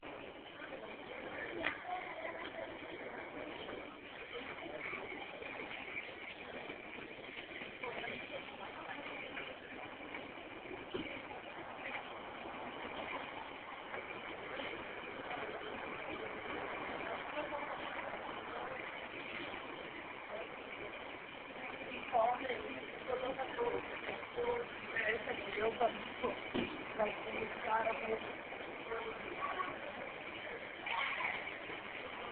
Hey, I recorded the sound of these crazy birds in the trees along 24th at York. It was around dusk, maybe 5:30 pm on Wednesday. The recording is crap because it came from my cell phone.
Bird's on 24th
I suspect you've recorded the same birds that I wake up to.